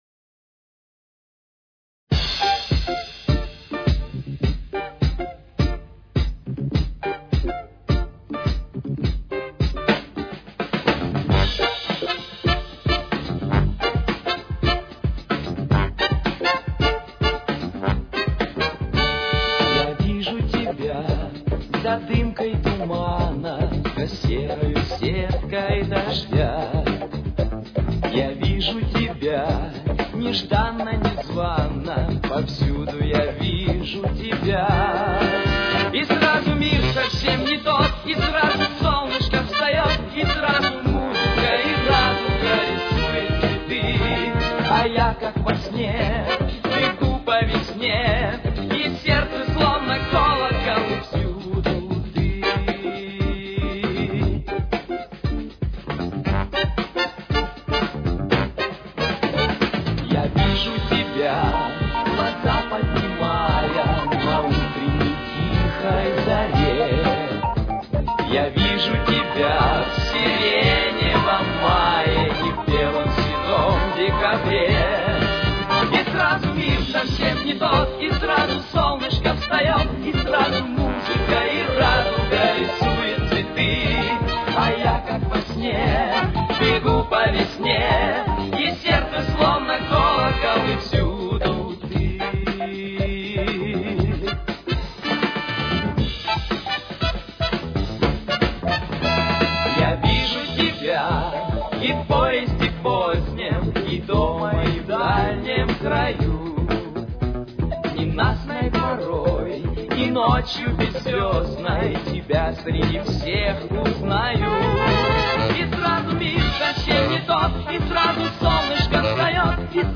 Тональность: Соль минор. Темп: 118.